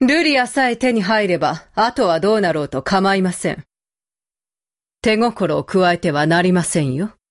Voice Actor Yūko Kaida
Menu Voice Lines